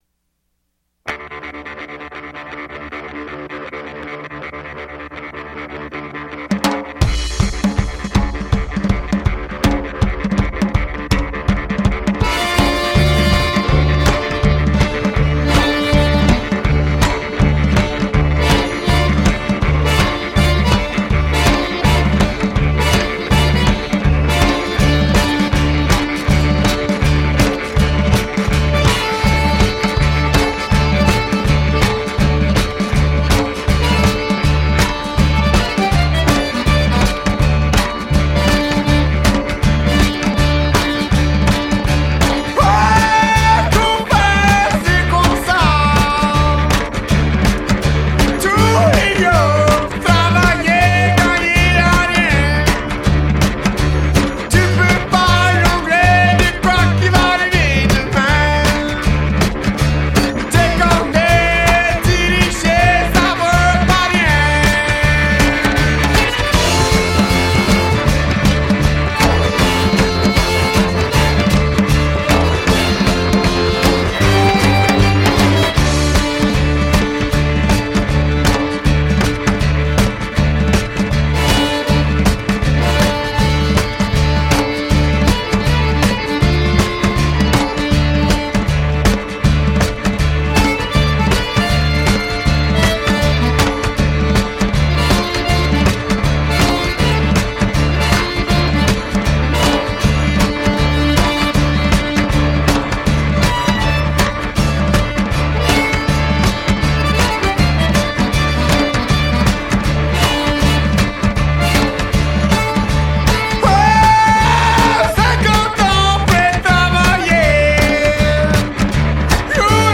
an eclectic mix of Cajun / creole / country swamp rock